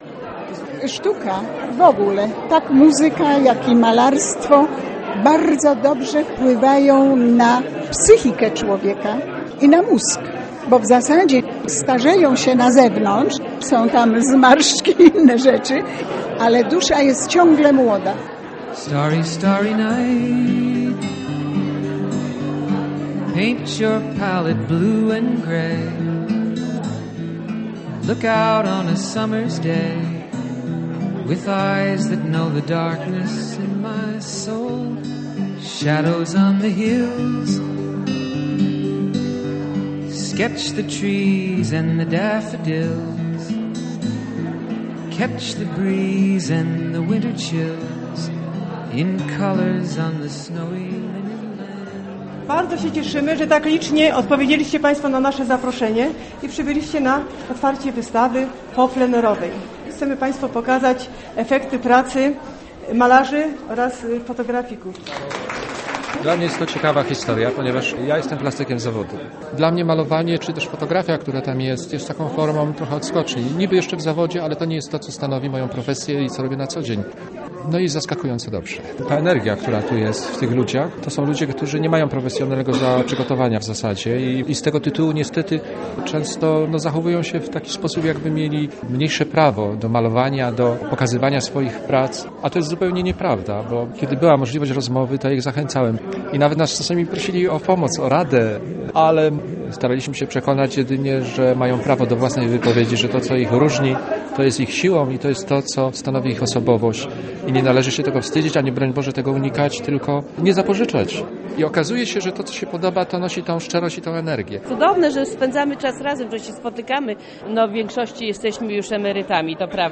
Plener - reportaż